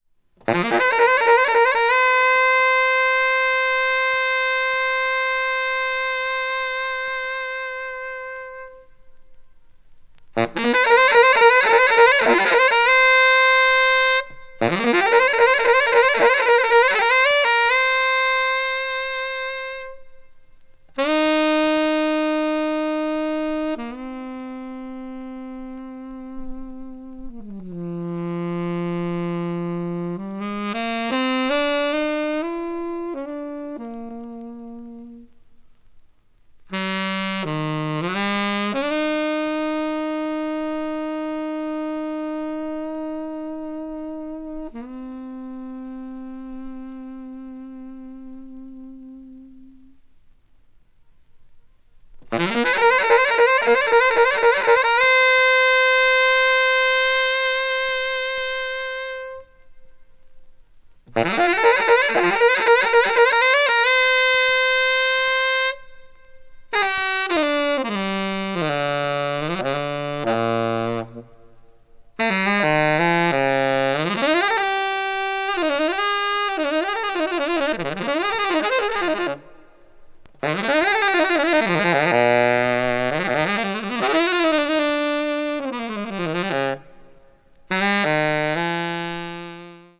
media : EX/EX(わずかにチリノイズが入る箇所あり)
jazz groove   post bop   spiritual jazz